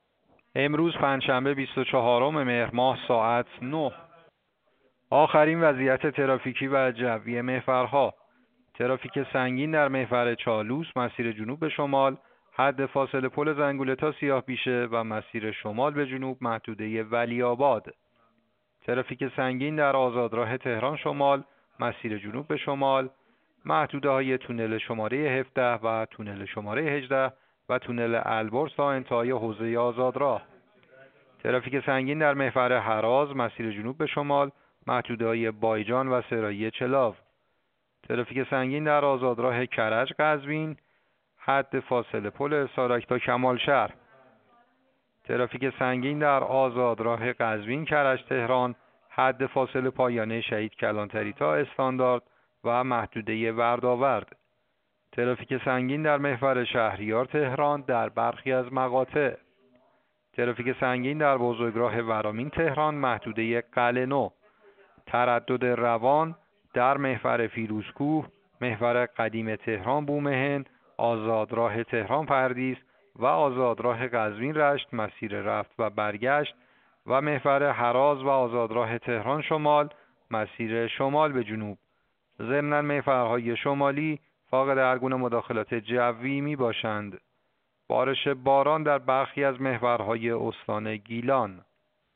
گزارش رادیو اینترنتی از آخرین وضعیت ترافیکی جاده‌ها ساعت ۹ بیست‌وچهارم مهر؛